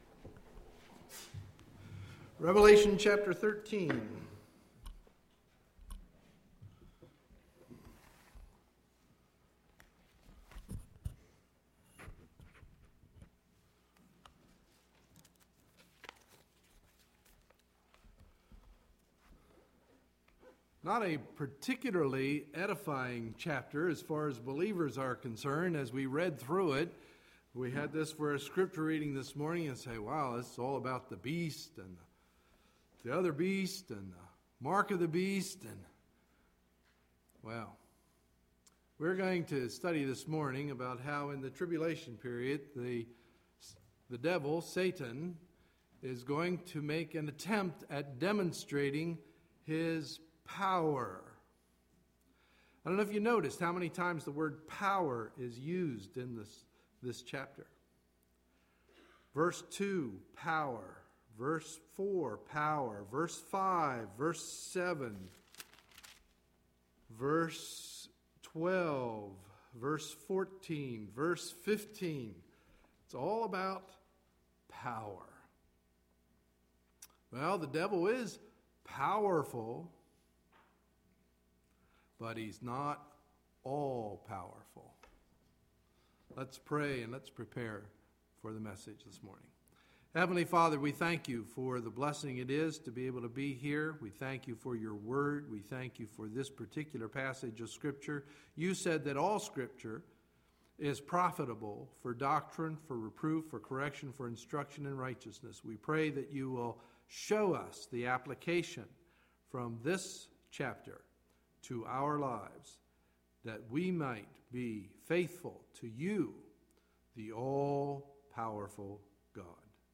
Sunday, October 2, 2011 – Morning Message
Sermons